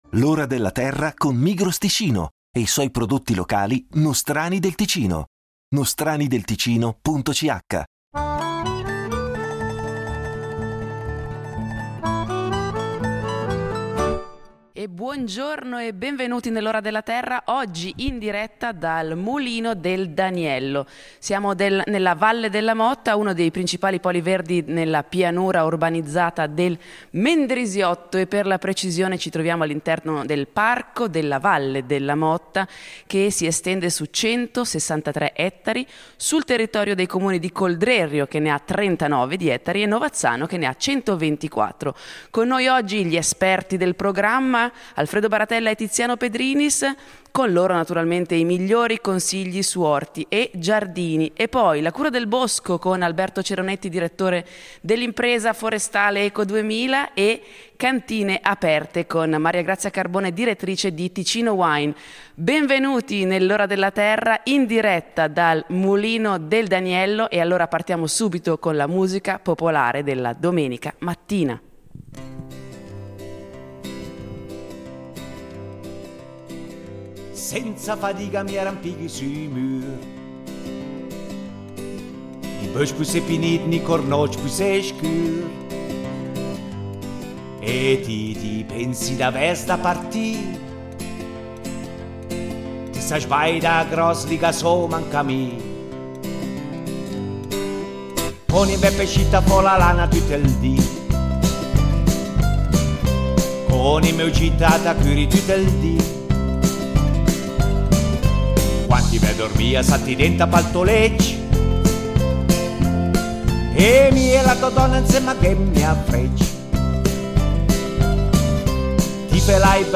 L’Ora della Terra va in scena in diretta dal Mulino del Daniello , all’interno della Valle della Motta, uno dei principali poli verdi nella pianura urbanizzata del Mendrisiotto.